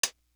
Closed Hats
D Elite Small Hat.wav